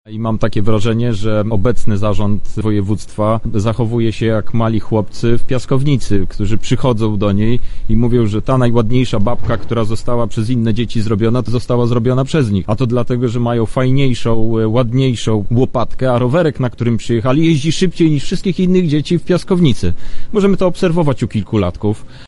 Zwracam się do członków zarządu województwa lubelskiego: bądźmy poważni – mówi europoseł Krzysztof Hetman: